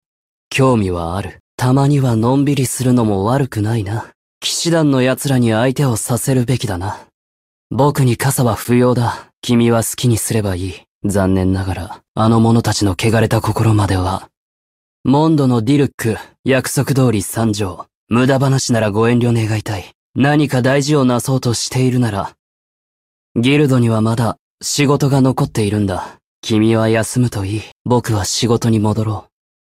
Japanese_DominantMan_Sourse.mp3